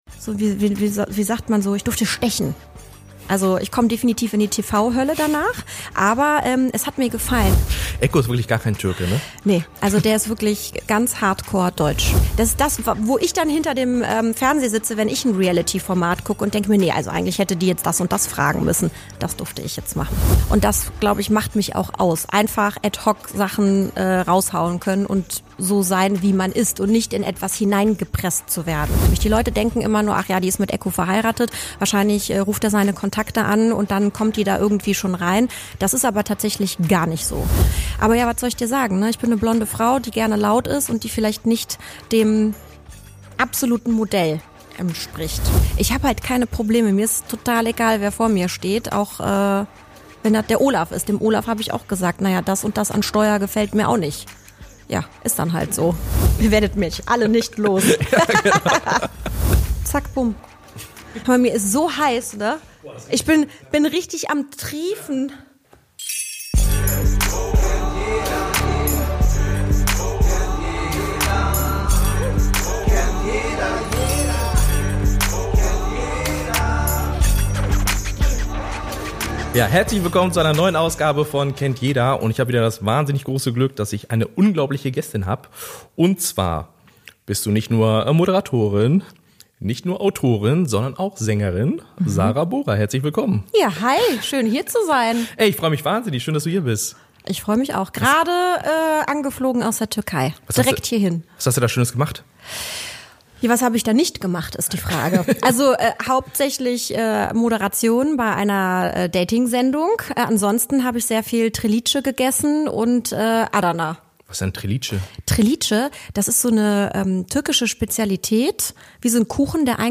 Beschreibung vor 6 Monaten In der neuen Folge von „Kennt jeder“ ist Sarah Bora zu Gast – Moderatorin, Autorin und erfolgreiche Schlagersängerin.
Eine Folge voller Energie, Ehrlichkeit und guter Laune.